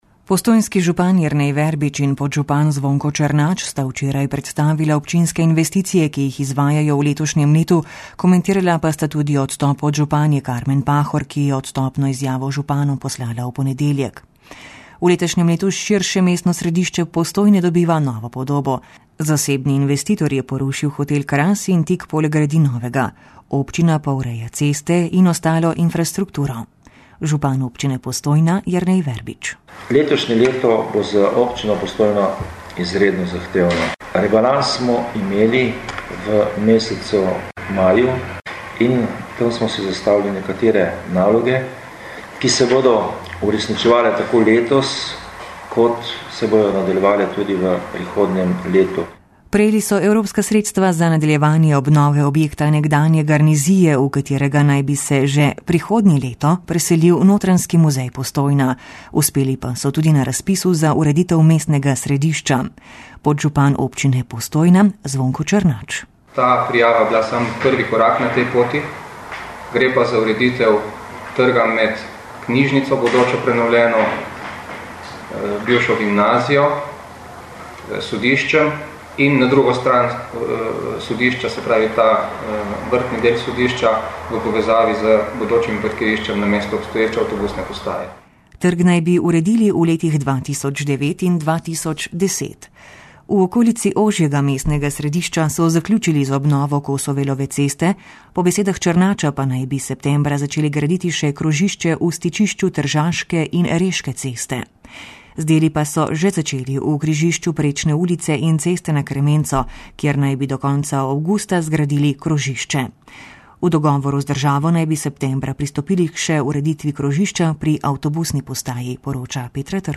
Postojnski župan Jernej Verbič in podžupan Zvonko Černač sta na tiskovni konferenci predstavila občinske investicije. Nekatere bodo kmalu dokončane, druge še snujejo.